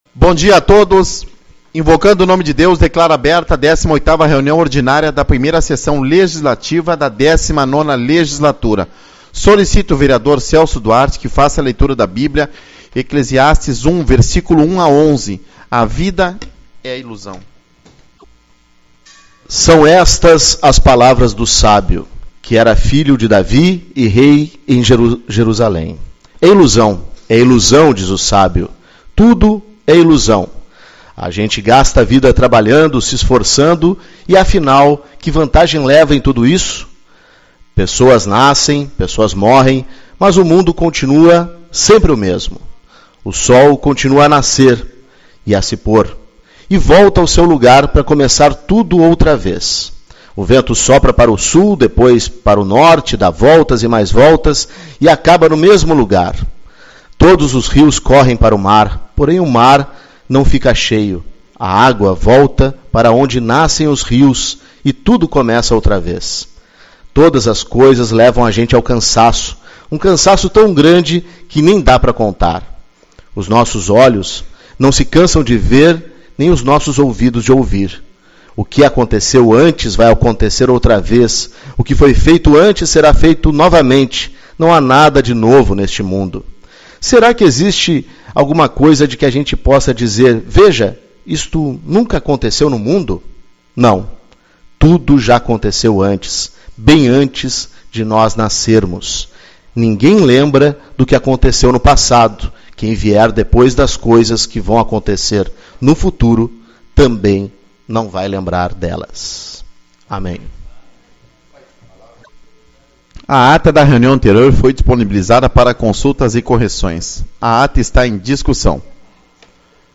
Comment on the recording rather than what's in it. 08/04 - Reunião Ordinária